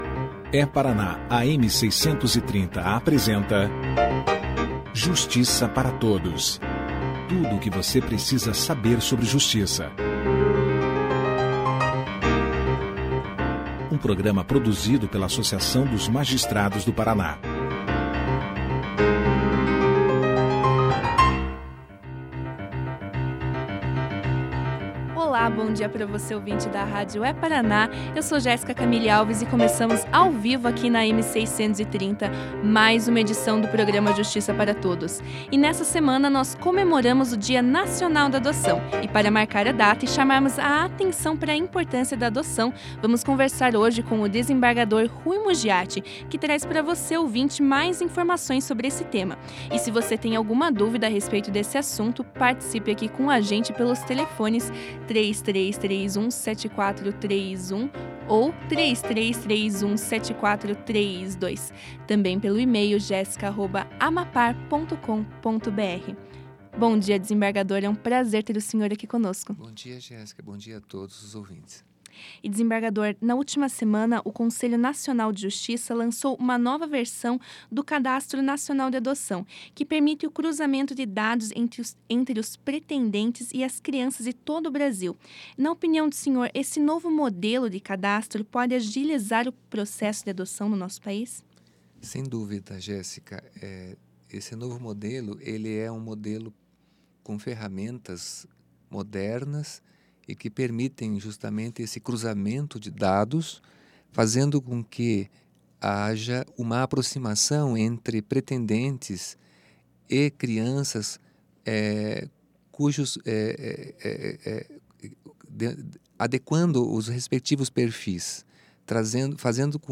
Ouça a entrevista do desembargador Ruy Muggiati sobre adoção na íntegra.
No programa de rádio da AMAPAR dessa quinta-feira (28) o desembargador Rui Mugiatti falou aos ouvintes da rádio É-Paraná sobre o tema adoção. O desembargador começou explicando aos ouvintes sobre a nova versão do Cadastro Nacional de Adoção, que permite o cruzamento de dados entre os pretendentes e as crianças de todo o Brasil.